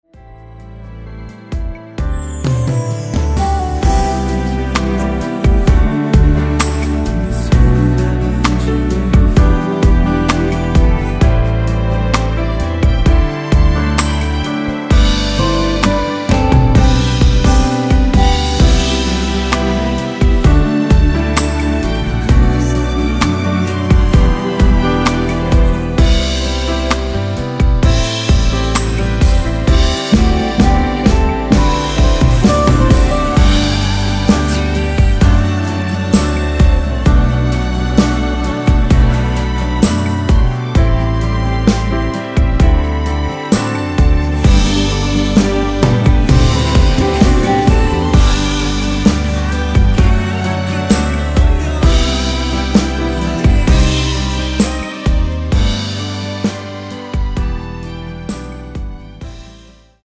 원키 코러스 포함된 MR 입니다.(미리듣기 참조)
앞부분30초, 뒷부분30초씩 편집해서 올려 드리고 있습니다.
중간에 음이 끈어지고 다시 나오는 이유는